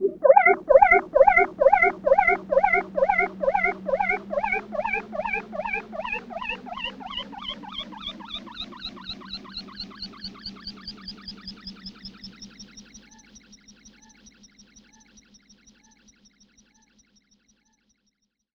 Drum and Bass
9 Rhodes FX 009.wav